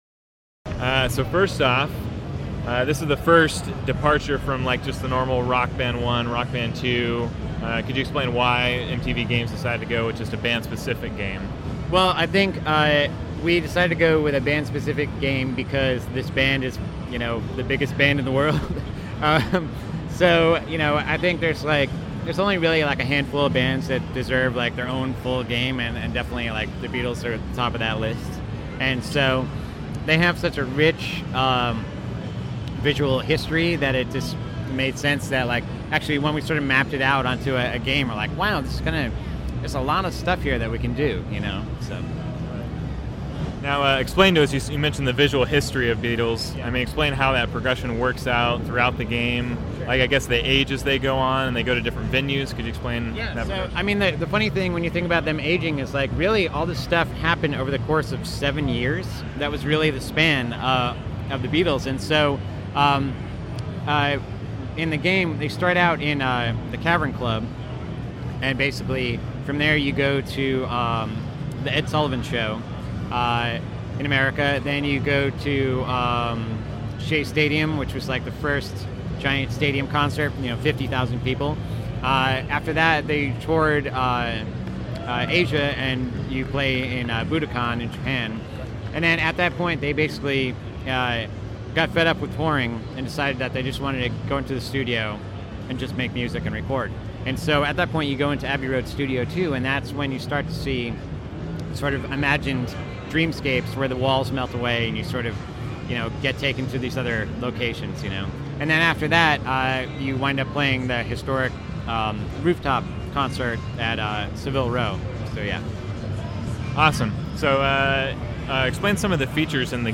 PN Audio Interview: The Beatles Rock Band
beatles_rb_interview.mp3